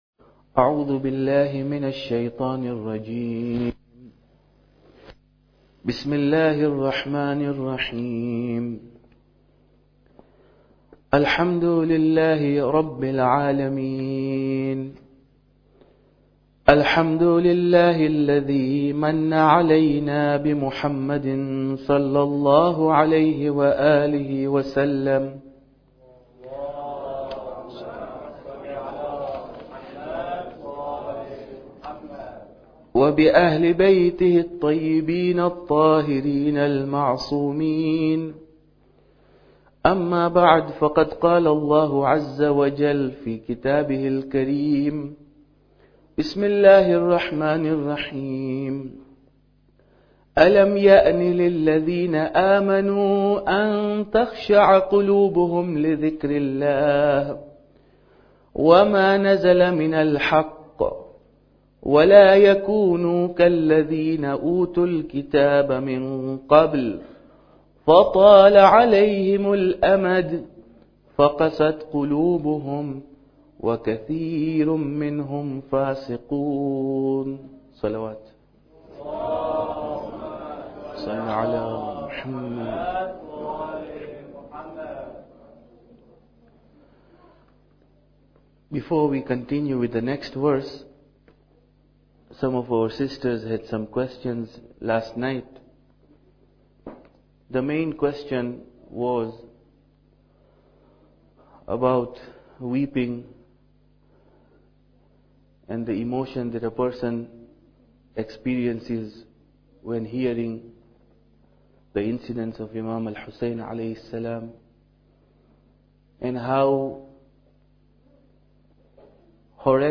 Muharram Lecture 7